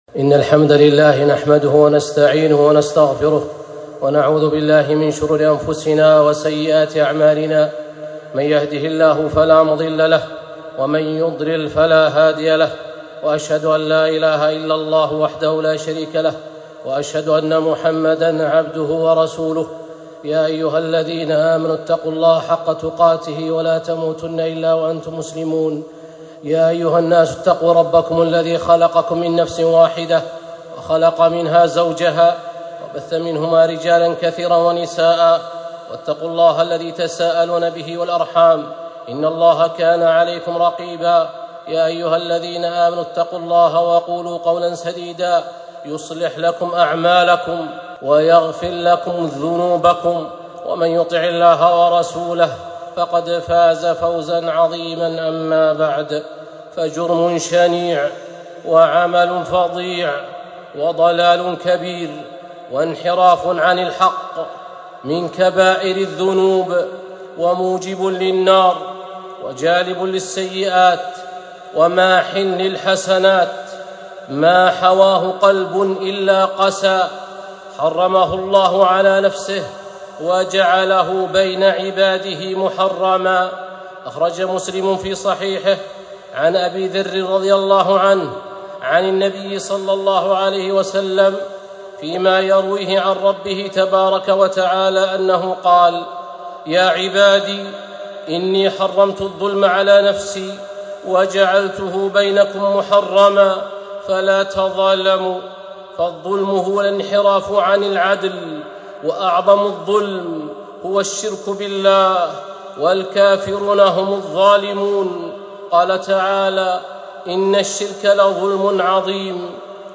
خطبة - الظلم ظلمات